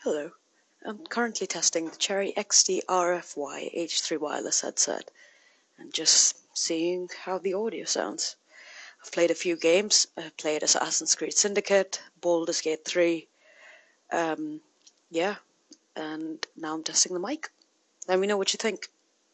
Микрофон с шумоподавлением
В приведенном выше ролике показано, как звучит микрофон, когда на заднем плане ничего не играет и нет других помех.
Для меня мой голос звучит чётко и ясно.